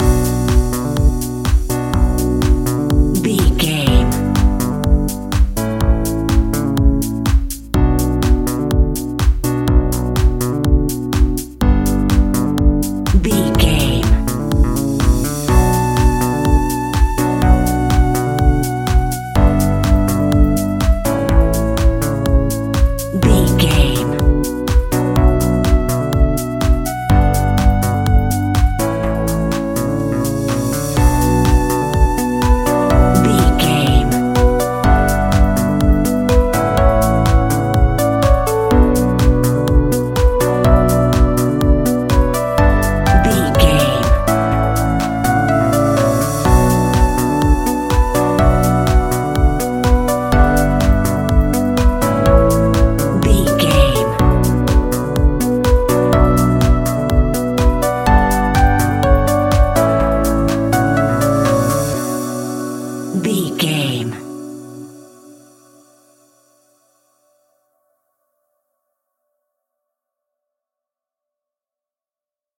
royalty free music
Epic / Action
Fast paced
Aeolian/Minor
groovy
uplifting
driving
energetic
drum machine
synthesiser
electro house
funky house
instrumentals
synth leads
synth bass